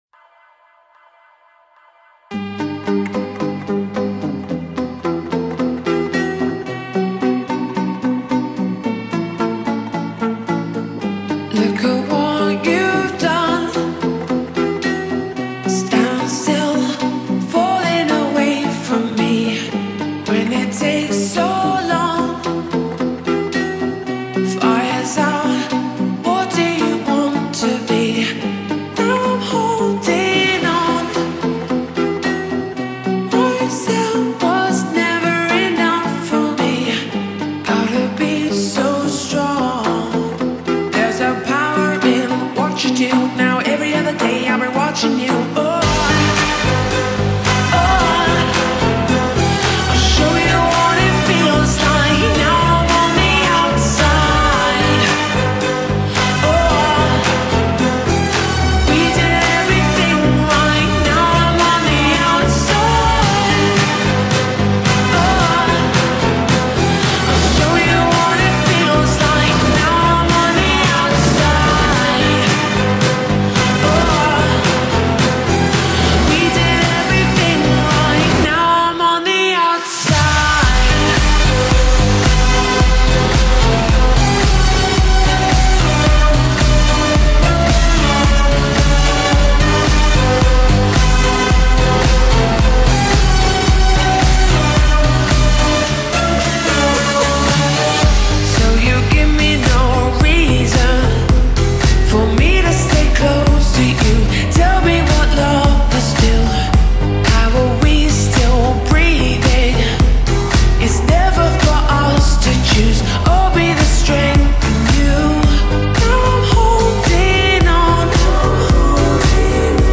باشگاهی